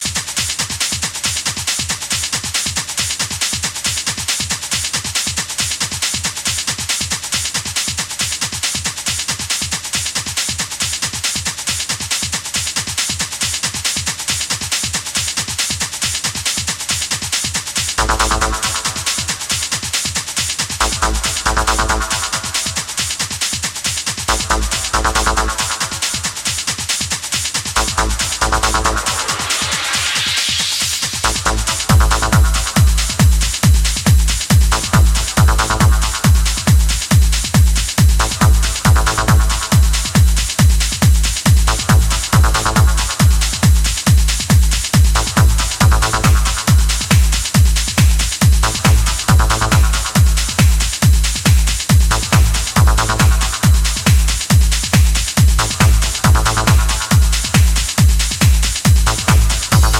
Электронная
Музыка в стиле Techno